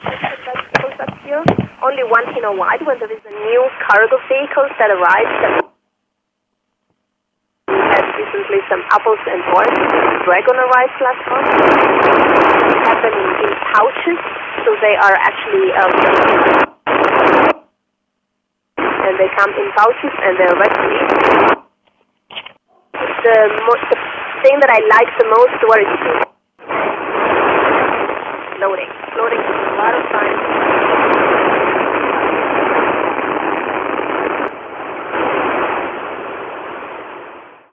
11th febr. 2015 Voice heard over Romania
I don't know who the lady was speaking to, school students probably.
My antenna at that time was a GP, not suitable for ISS contacts.